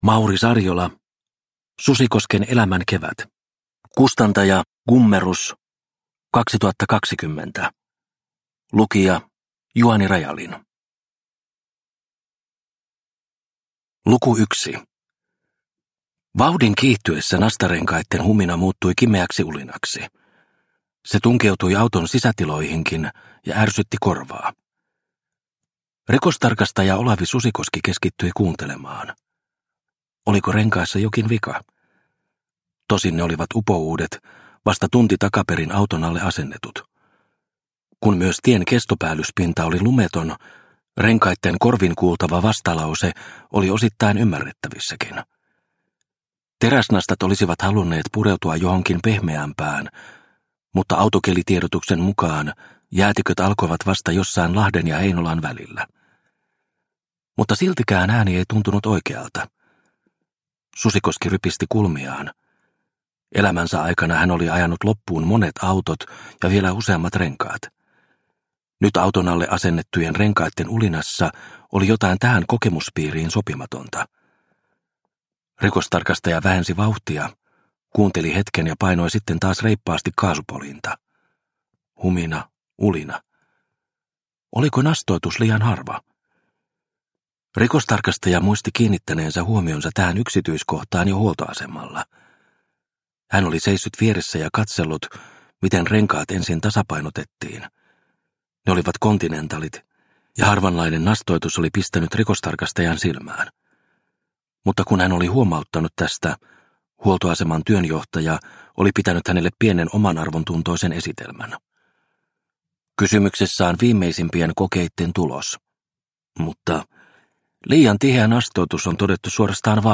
Susikosken elämän kevät – Ljudbok – Laddas ner